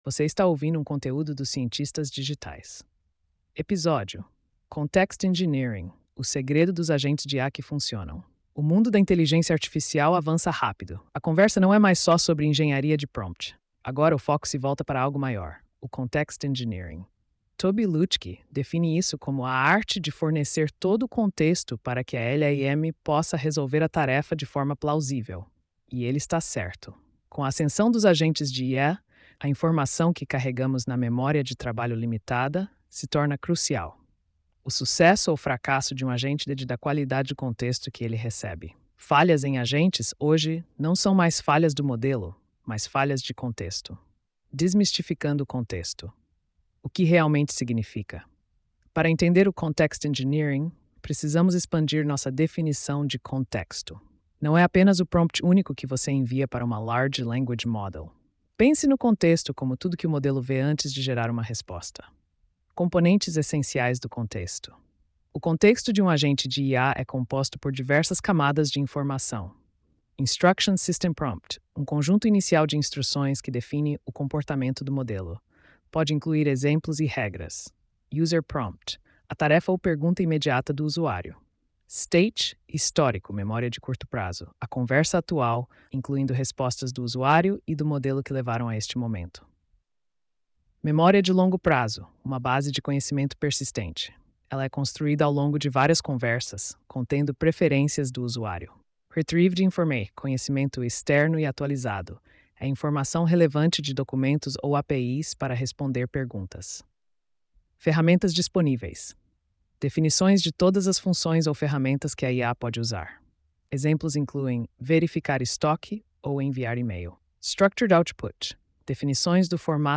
post-3246-tts.mp3